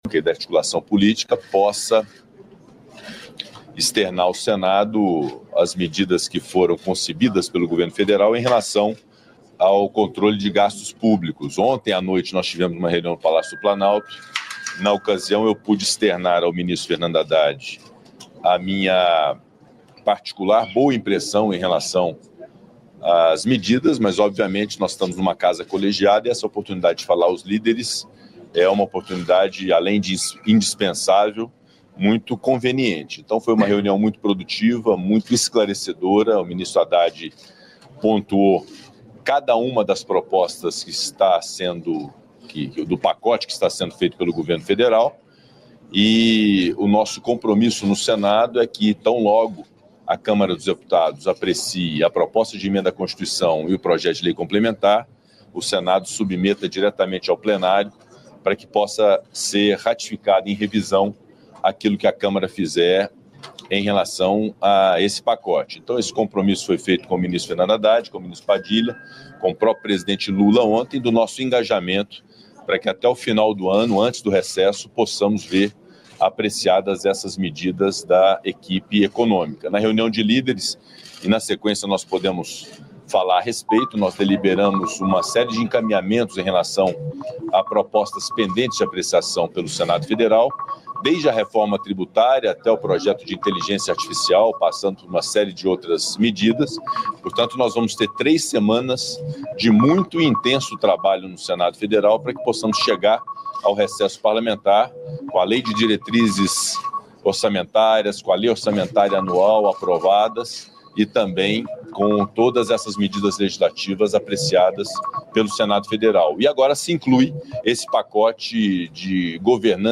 Íntegra da coletiva de imprensa com Alexandre Padilha, ministro das Relações Institucionais, Fernando Haddad, ministro da Fazenda, e o presidente do Senado Federal, Rodrigo Pacheco, nesta quinta-feira (28), em Brasília.